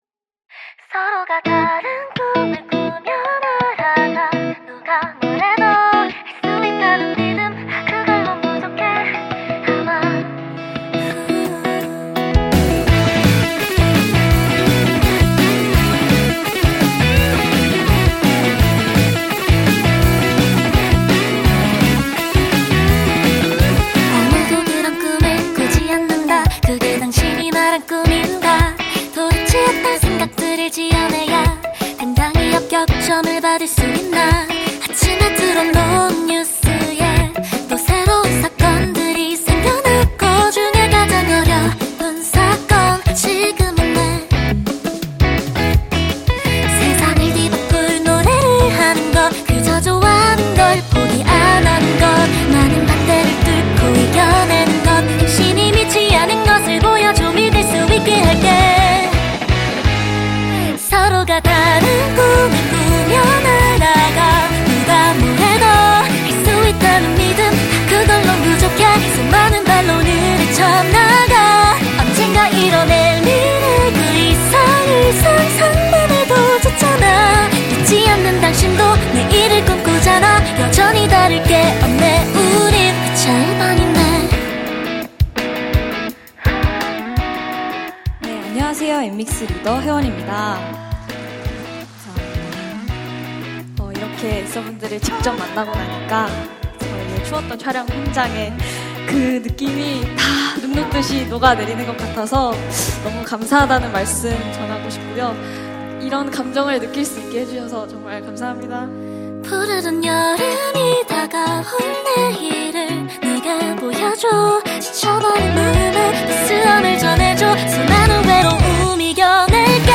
KPop Song
Label Ballad